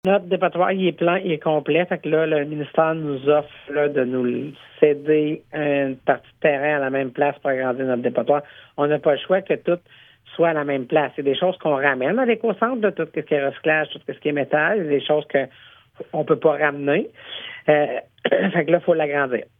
Le dépotoir Notakim, situé dans les territoires non organisés de la Vallée-de-la-Gatineau, va être agrandi pour répondre aux besoins locaux pour la gestion des matières résiduelles. La préfète de la MRC de La Vallée-de-la-Gatineau (MRCVG), Chantal Lamarche, commente le dossier :